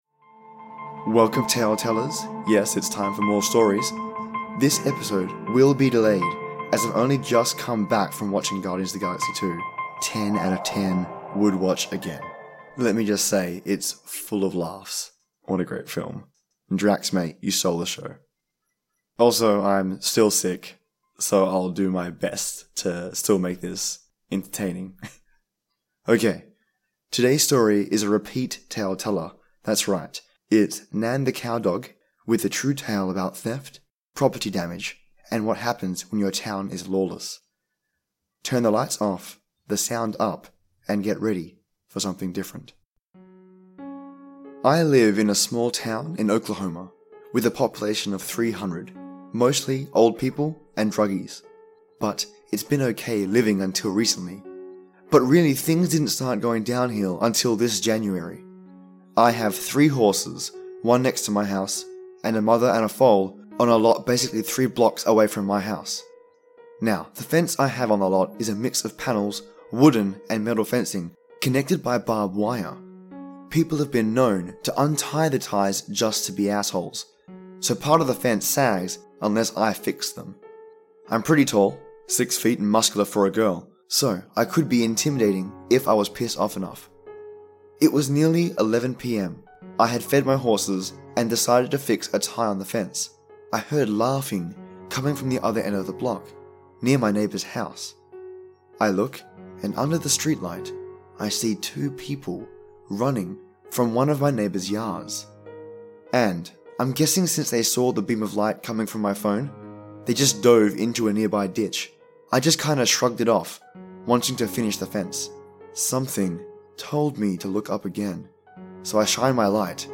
Did you enjoy this narration and stories?
Haunting Atmospheric Background Music